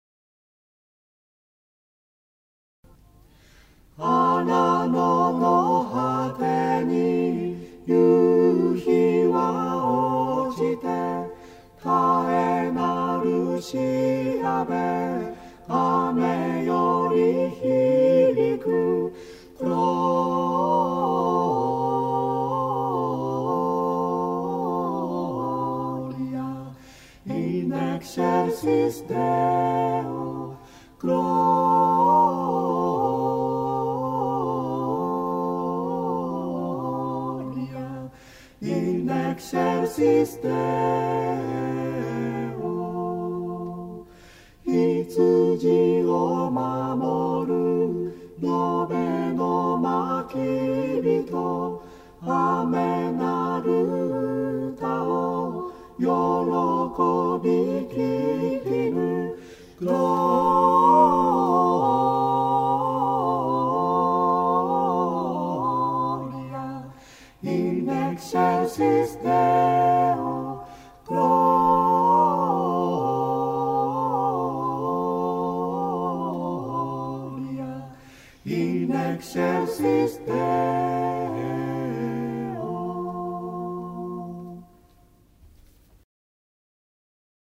讃美歌二曲
混声四重唱　1980年2月11日